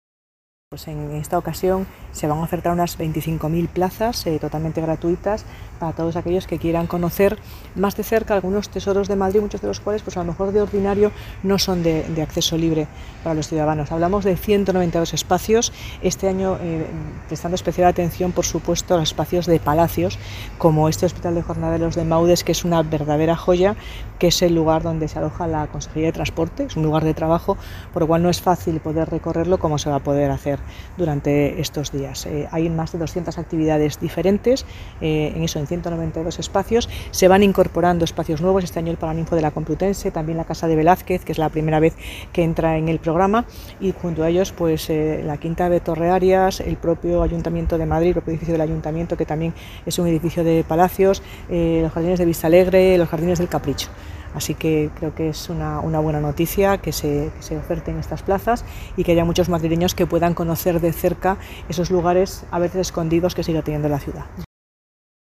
Nueva ventana:Declaraciones de la delegada de Cultura, Turismo y Deporte, Marta Rivera de la Cruz, durante la presentación de MoM2024